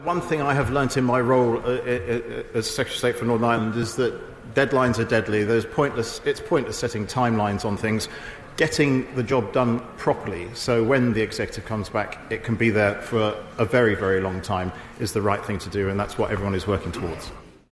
Speaking in the British Parliament, Chris Heaton Harris says there’s absolutely no point in deadlines: